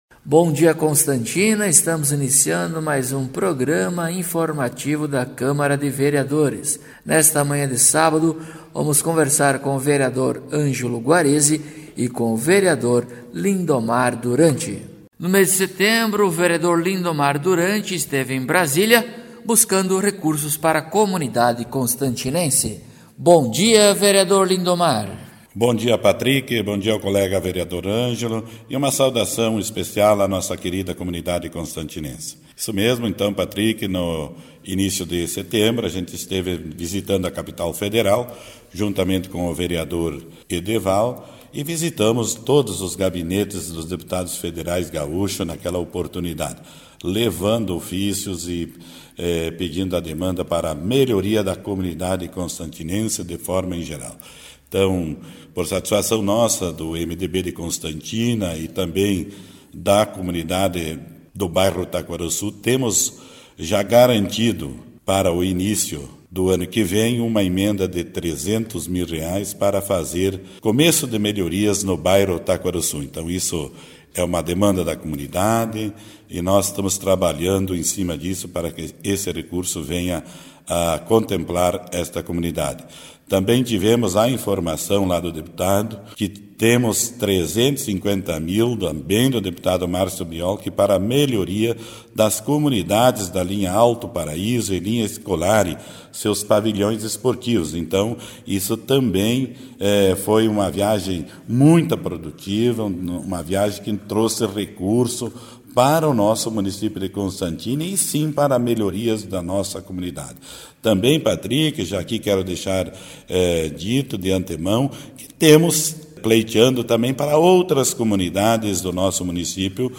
Acompanhe o programa informativo da câmara de vereadores de Constantina com o Vereador Ângelo Guarezi e o Vereador Lindomar Duranti.